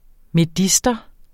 Udtale [ meˈdisdʌ ]